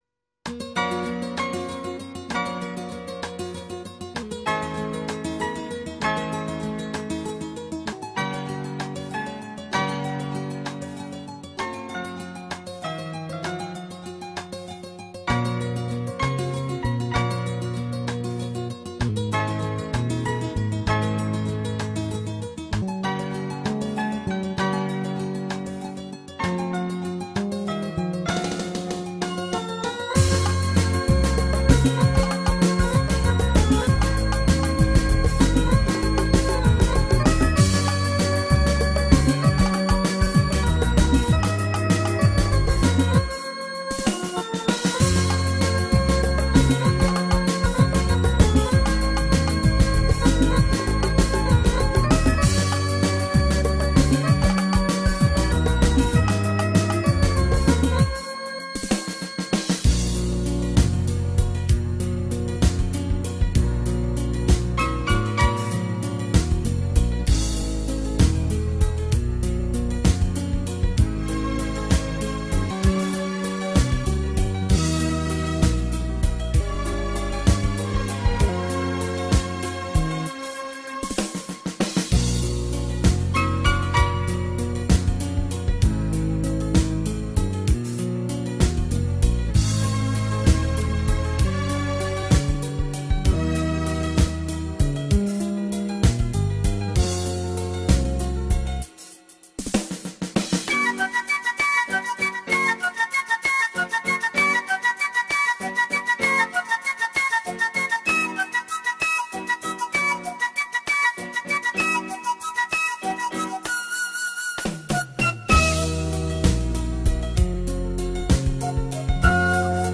نسخه بیکلام کارائوکه تمرین خوانندگی